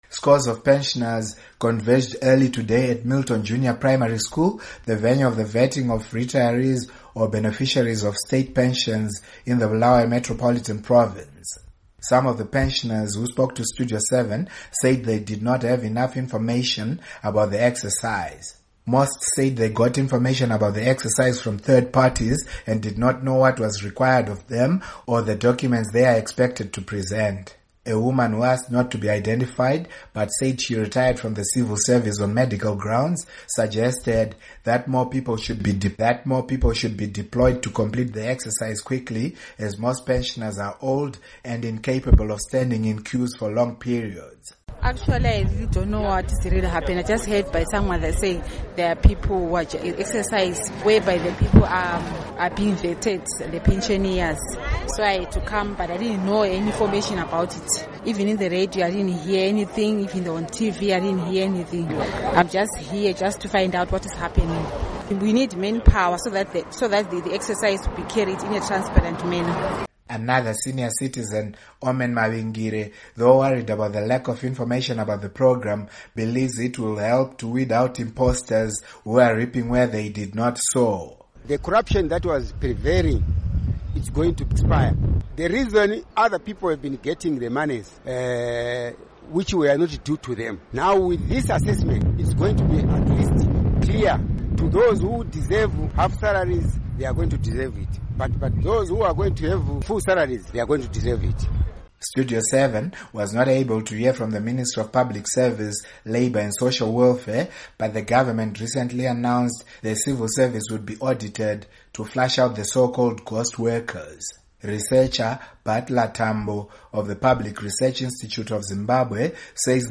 Report on Pensioners